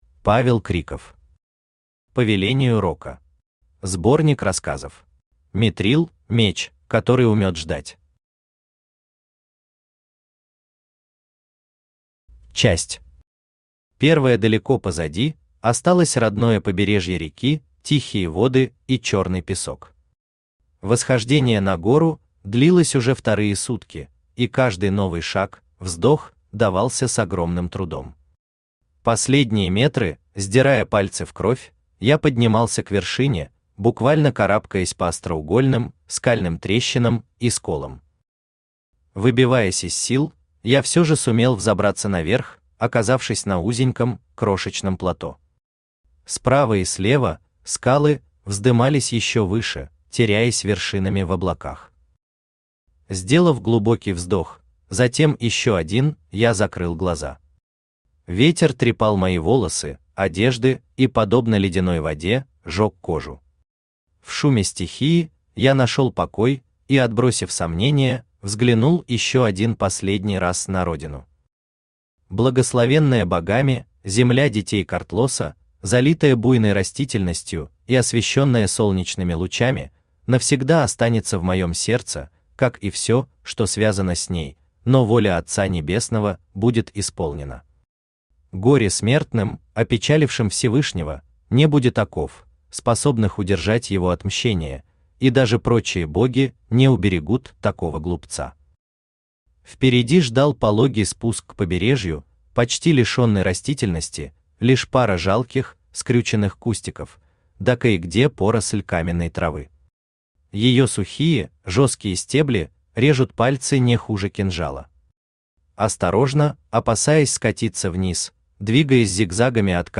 Аудиокнига По велению рока. Сборник рассказов | Библиотека аудиокниг
Сборник рассказов Автор Павел Криков Читает аудиокнигу Авточтец ЛитРес.